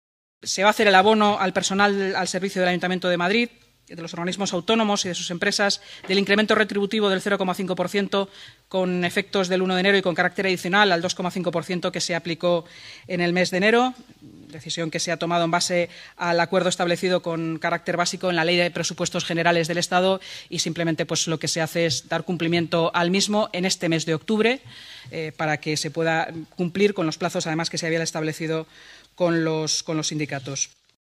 Nueva ventana:La vicealcaldesa de Madrid y portavoz municipal, Inma Sanz